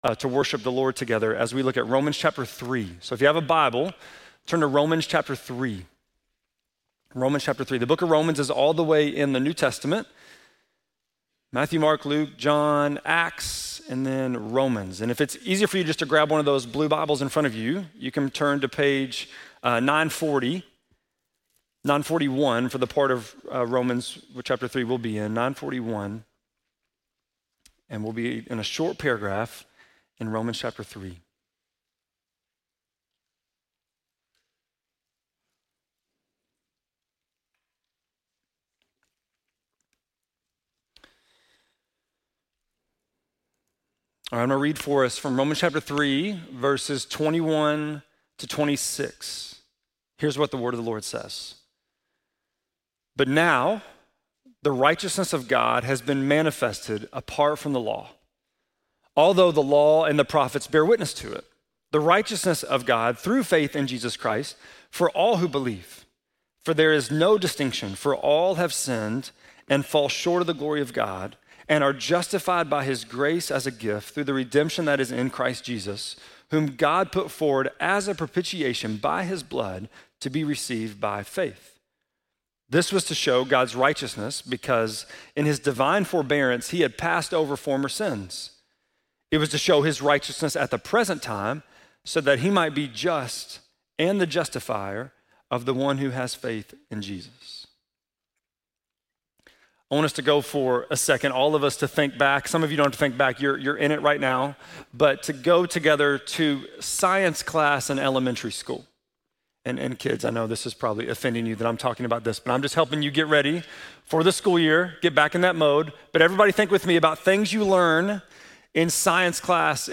8.3-sermon-2.mp3